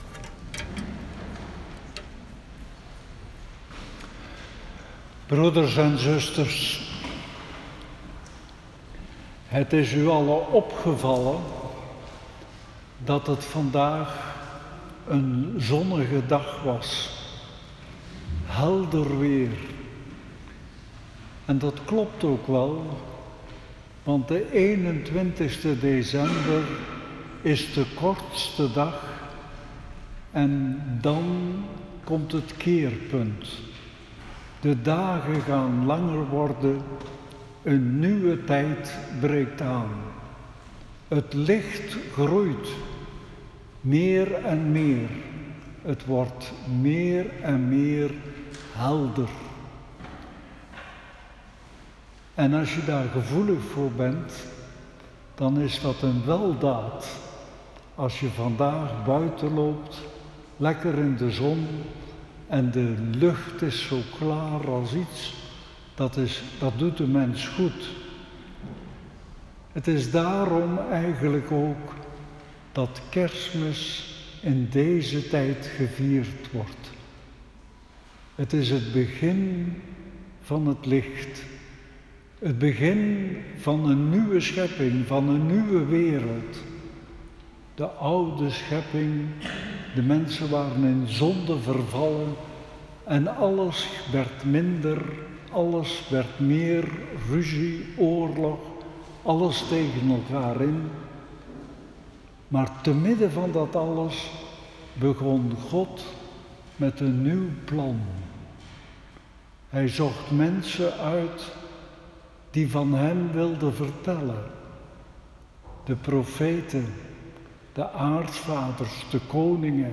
Viering 24 december 2019
Preek..m4a